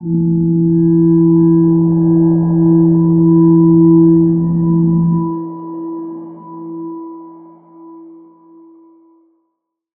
G_Crystal-E4-pp.wav